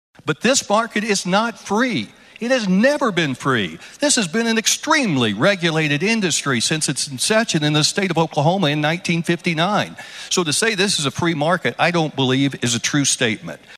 CLICK HERE to listen to commentary from State Senator Bill Coleman.